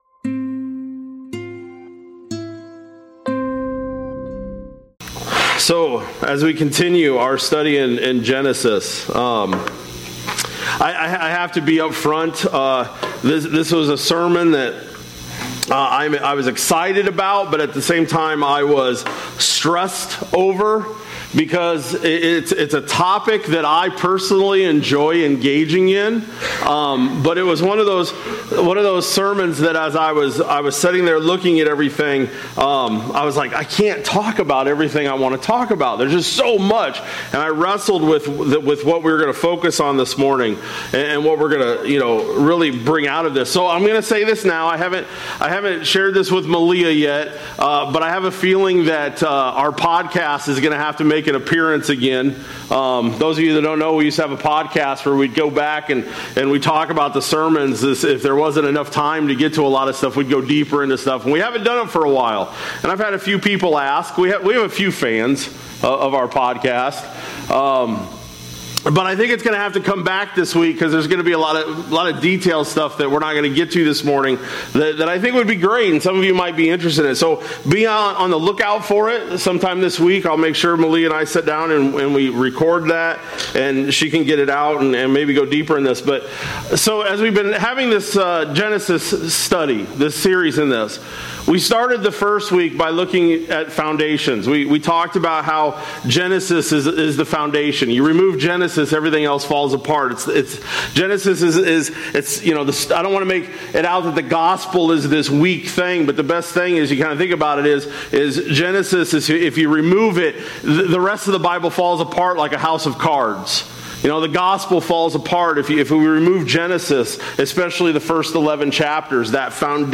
Jan-25-26-Sermon-Audio.mp3